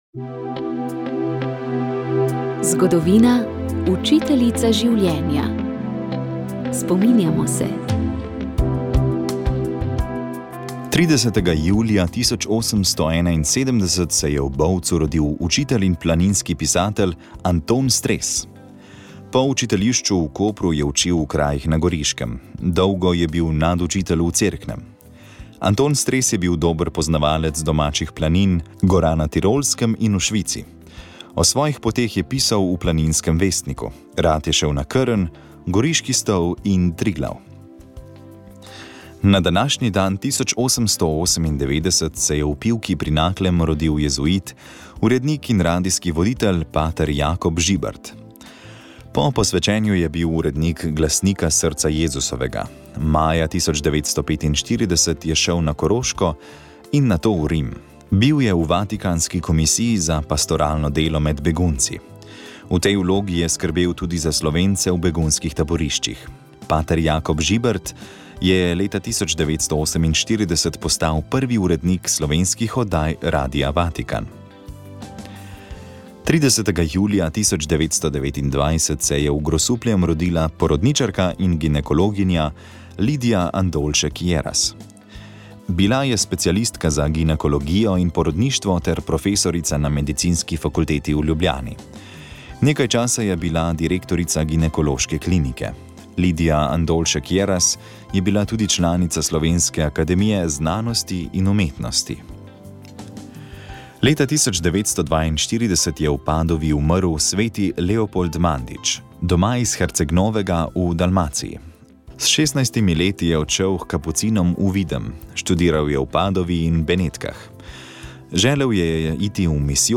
V oddaji Utrip Cerkve v Sloveniji smo objavili reportažo iz Župnije Sodražica, kjer smo se udeležili svete maše ob obletnici smrti Božje služabnice Magdalene Gornik.